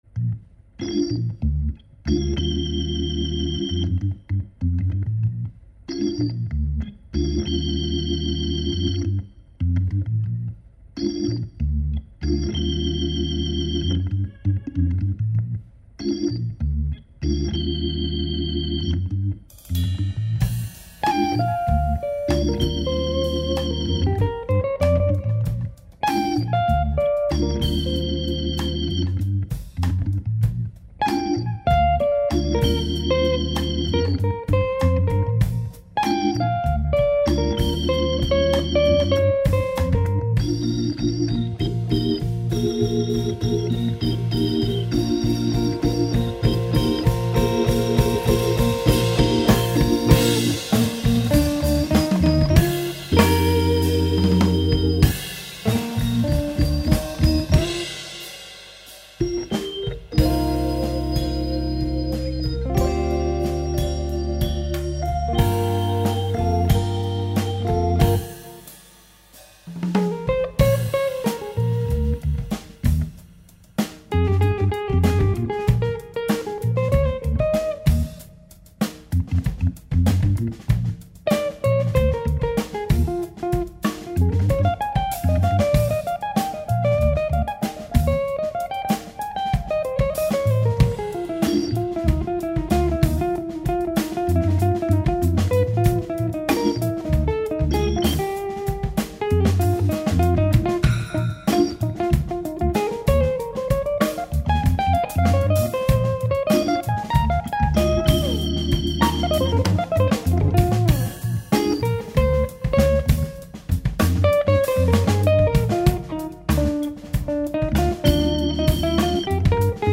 ça groove